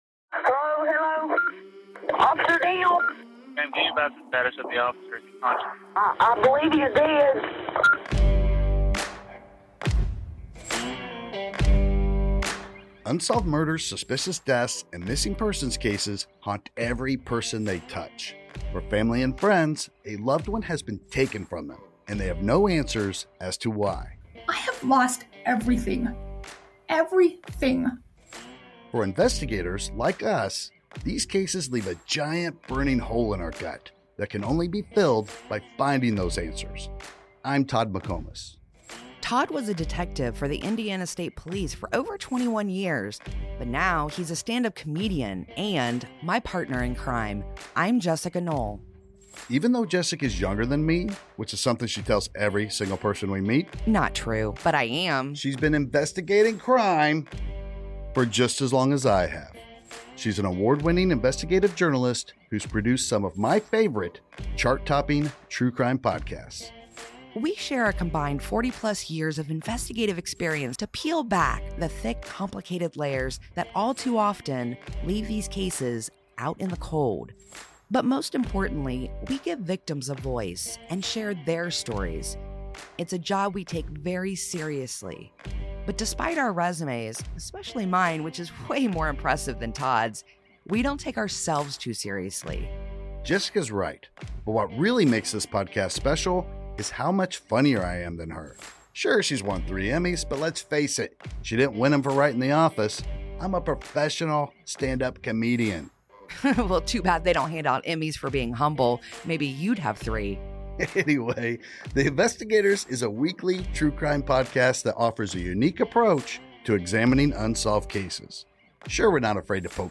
But, because they don't take themselves seriously, listeners get the expert analysis and commentary they should expect, mixed with enough witty banter to feel like they're among friends.